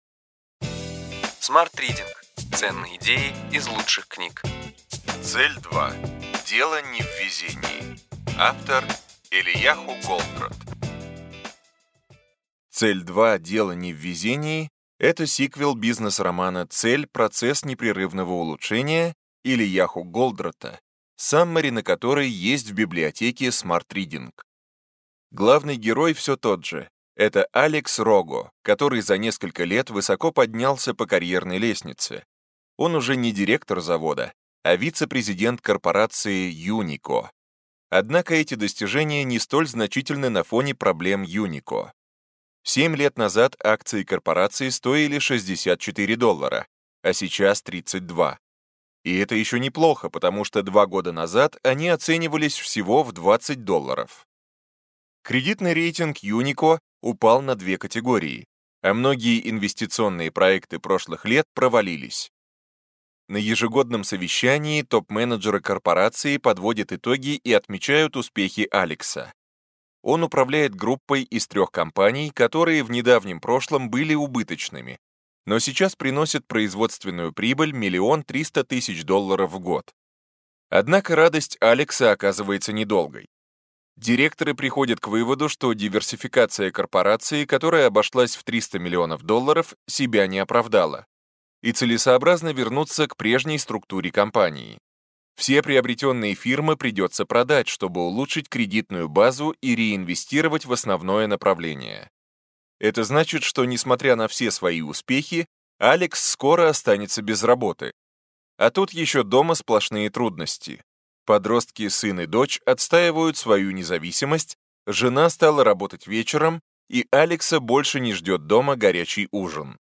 Аудиокнига Ключевые идеи книги: Цель-2. Дело не в везении. Элияху Голдратт | Библиотека аудиокниг